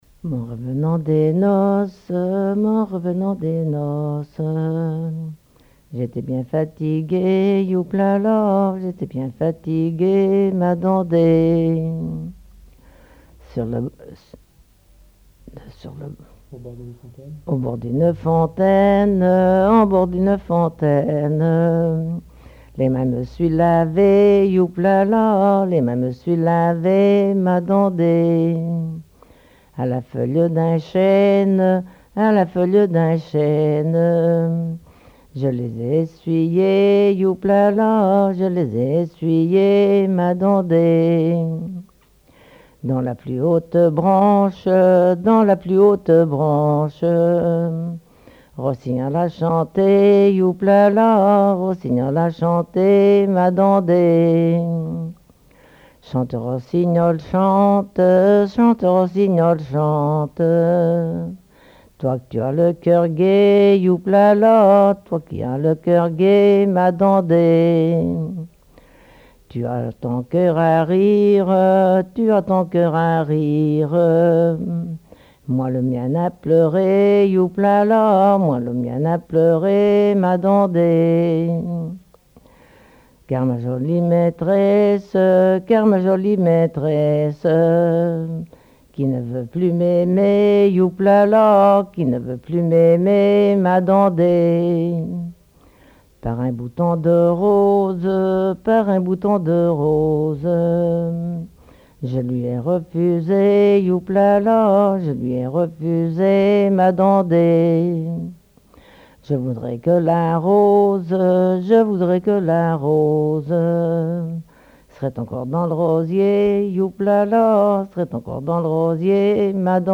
collecte en Vendée
chansons traditionnelles
Pièce musicale inédite